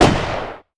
EXPLOSION_Arcade_13_mono.wav